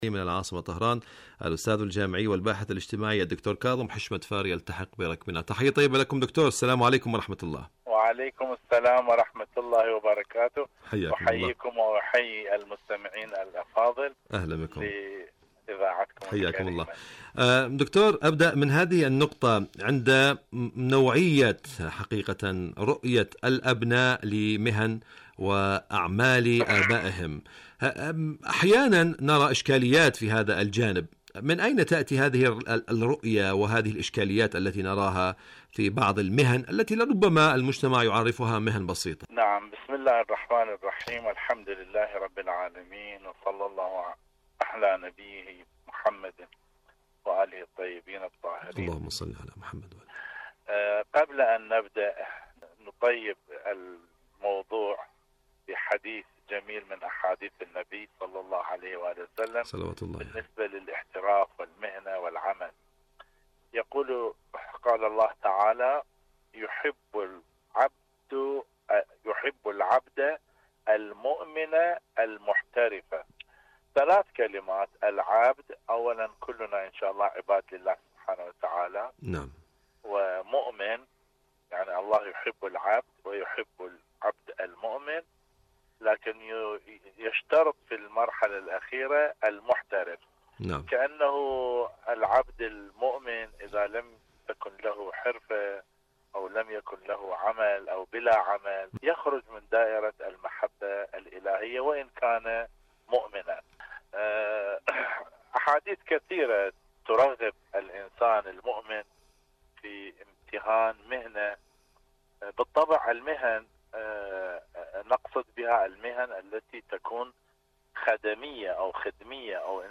إذاعة طهران- معكم على الهواء: مقابلة إذاعية